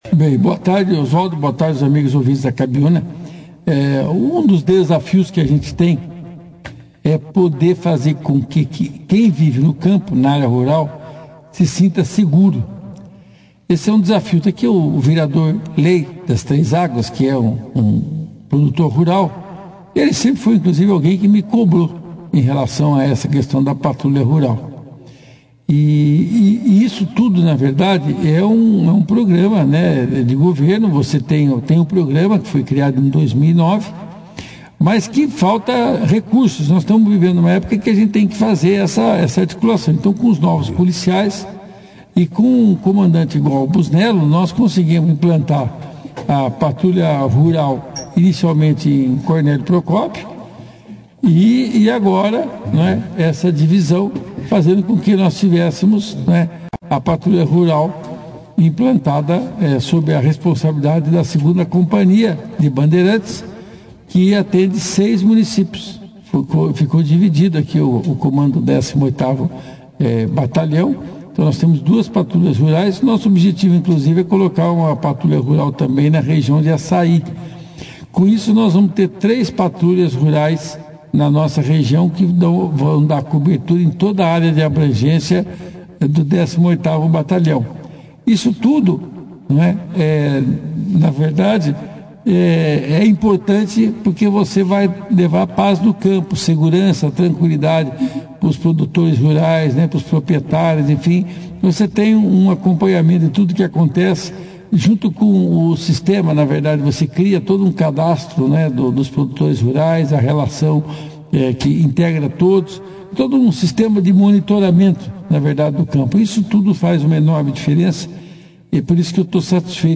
O Deputado Estadual Luiz Claudio Romanelli foi destaque na manhã desta sexta-feira, 16 de fevereiro, ao participar ao vivo nos estúdios da Cabiúna FM, dentro da 2ª edição do jornal Operação Cidade. Sua presença se deu logo após a entrega oficial de uma viatura Mitsubishi L200 Triton à 2ª Companhia de Polícia Militar de Bandeirantes, destinada ao Policiamento de Patrulha Rural Comunitária.